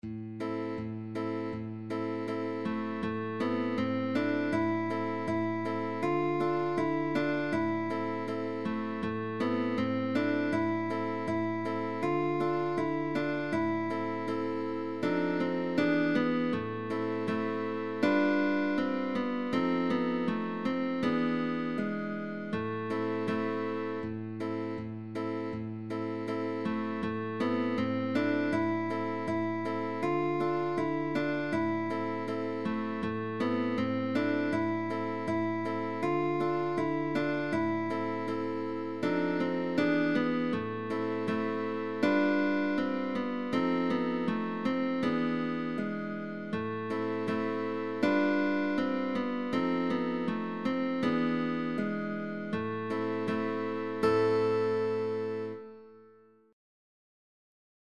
very easy by guitar duo
GUITAR DUO (Pupil & Teacher)